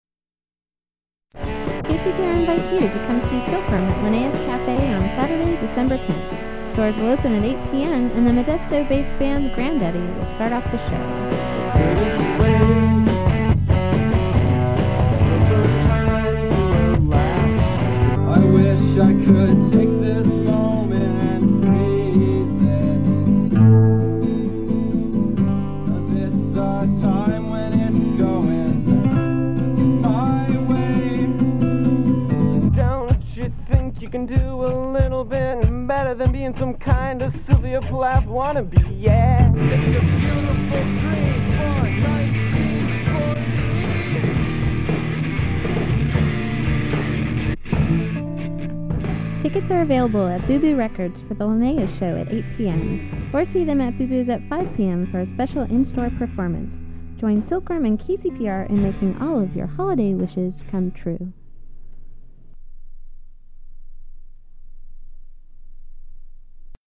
Promotional recording
Form of original Audiocassette